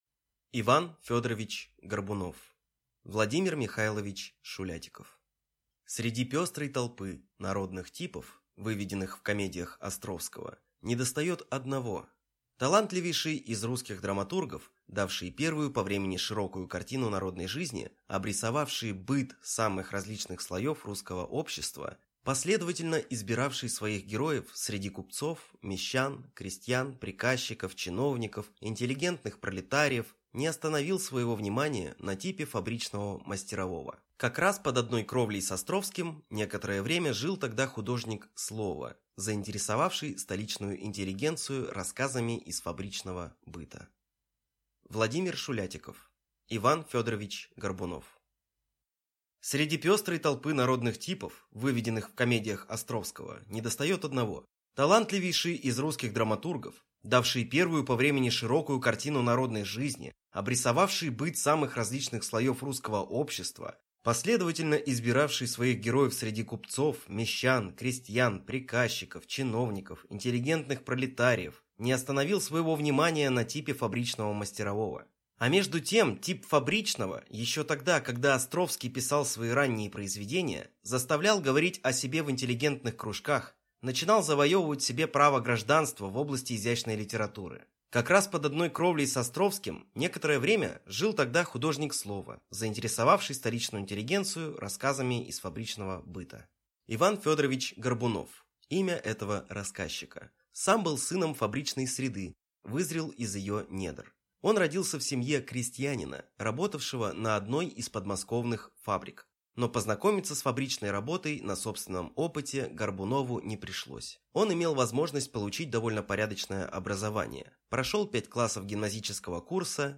Аудиокнига И. Ф. Горбунов | Библиотека аудиокниг